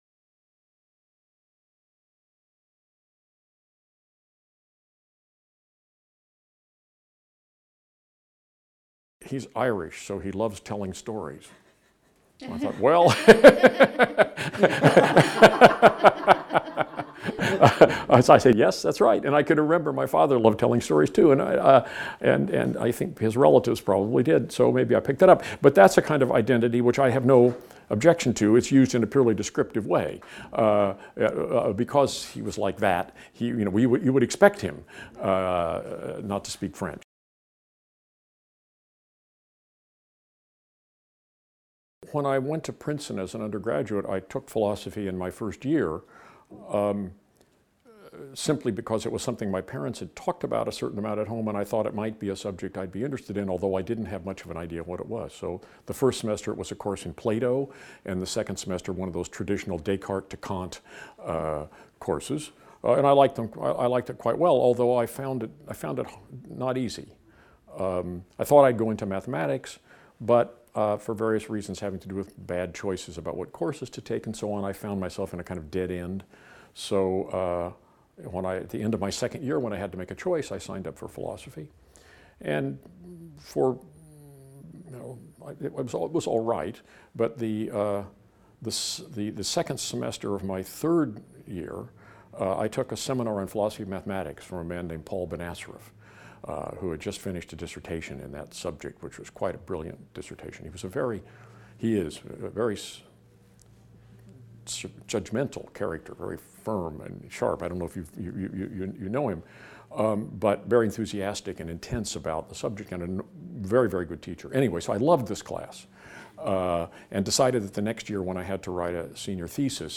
Interview with Tim Scanlon | Canal U